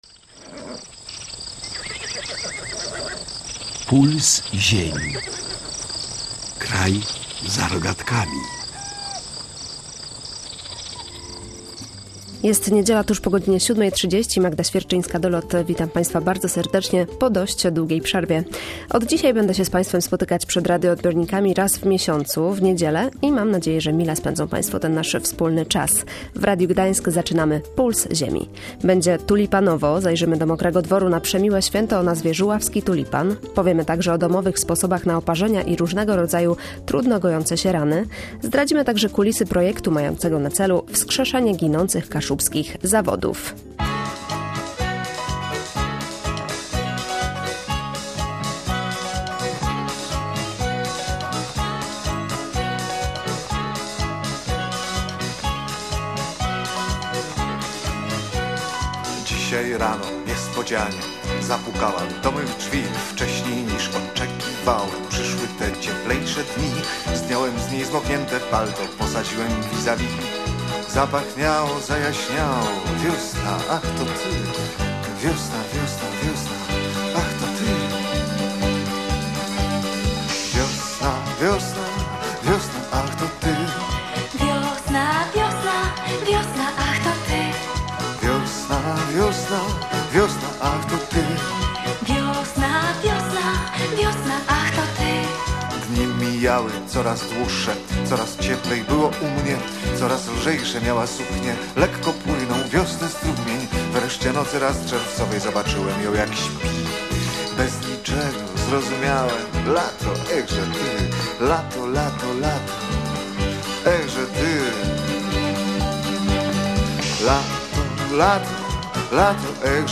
Towarzyszyliśmy w zmaganiach uczestnikom konkursu na najpiękniejszą kwiatową kompozycję. W audycji mówiliśmy też o domowych – miodowych – sposobach na oparzenia i różnego rodzaju trudno gojące się rany.